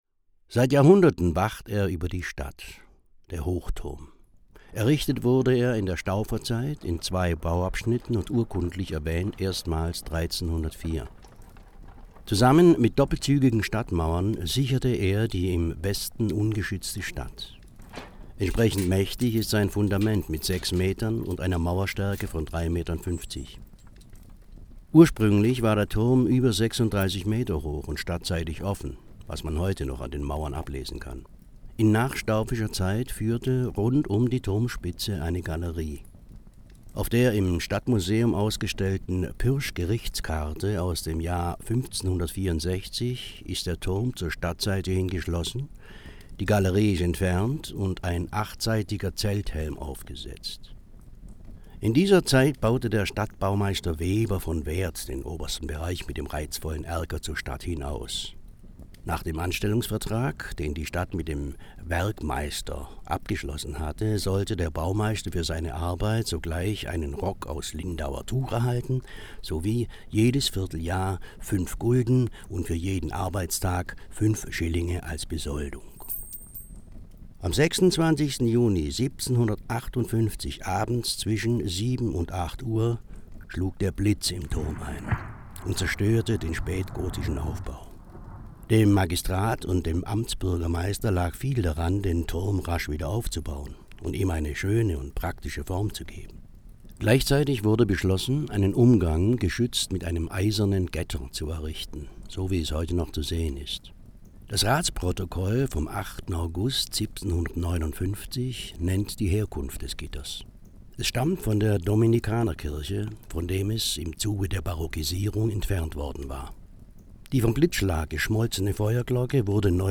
Audioguide Rottweil | 09. Hochturm